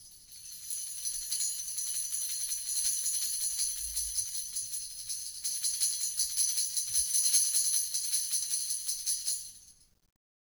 Percussion
Tamb1-Roll_v1_rr1_Sum.wav